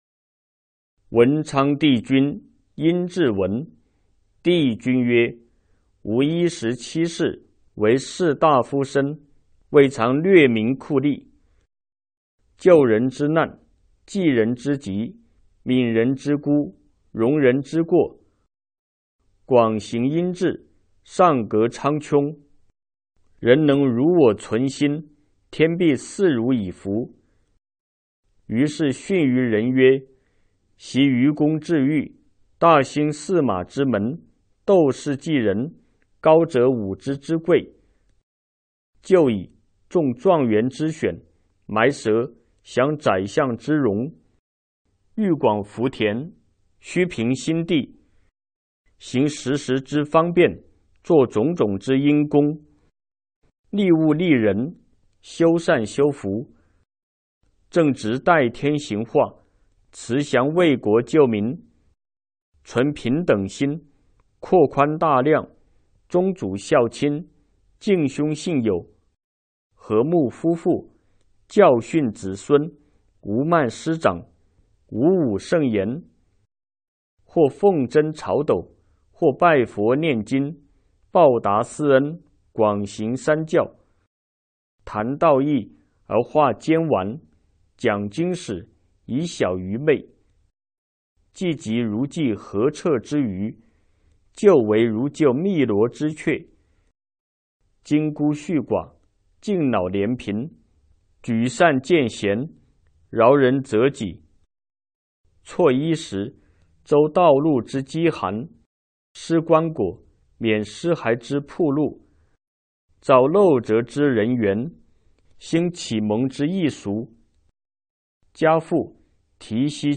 有声书